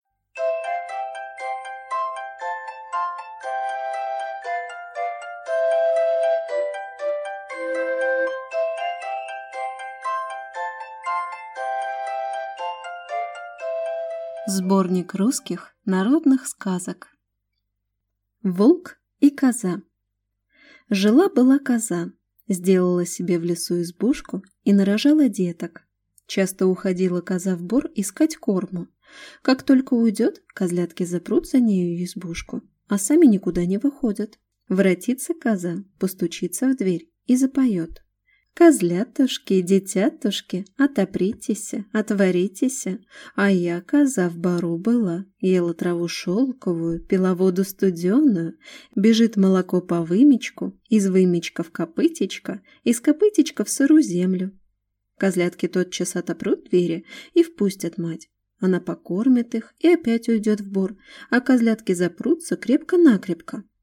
Аудиокнига Волк и коза, и другие сказки | Библиотека аудиокниг